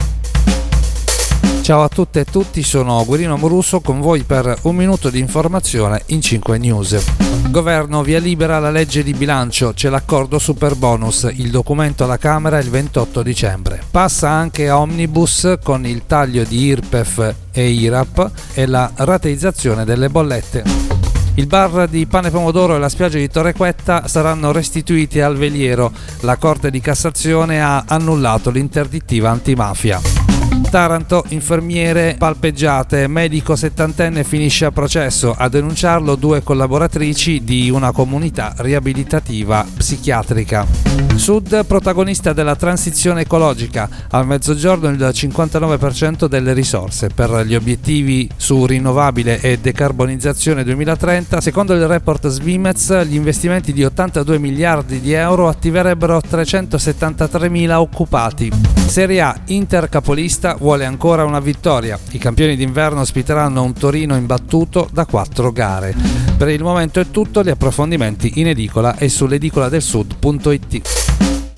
Giornale radio alle ore 7.